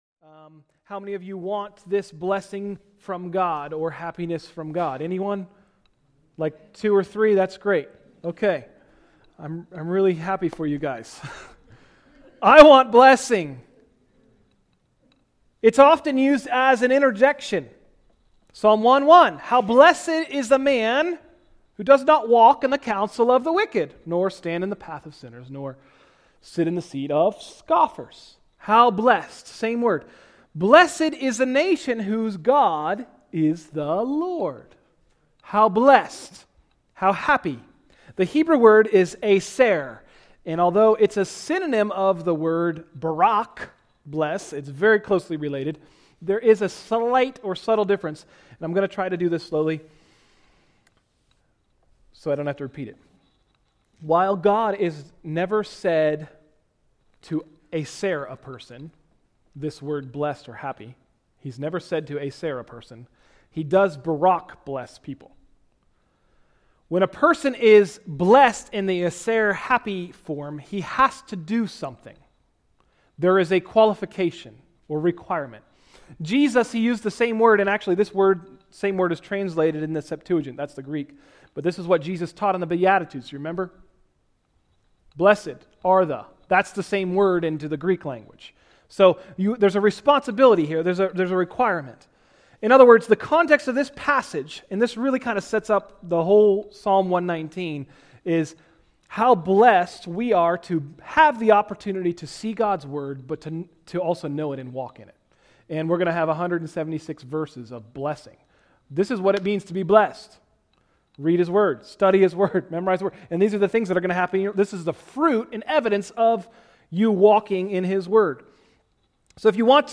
Sermons – Tried Stone Christian Center
(The first couple of minutes of the recording were lost)